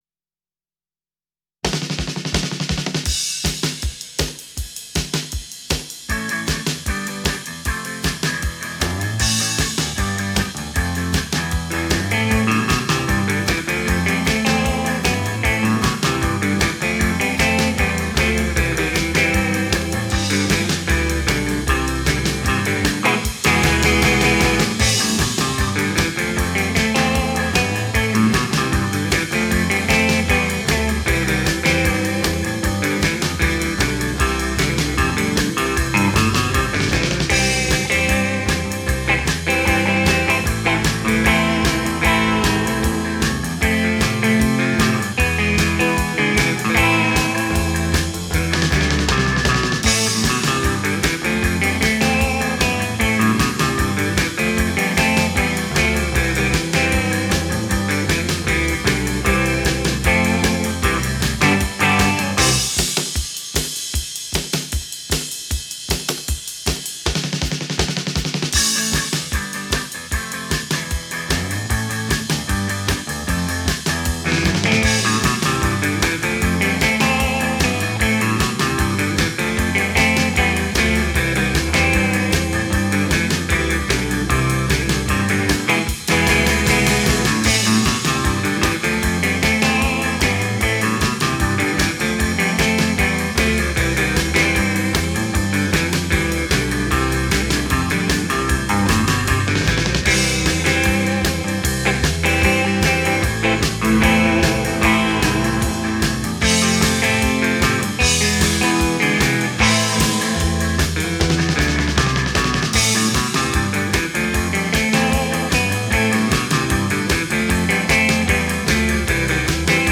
Genre: Pop, Rock & Roll, Beat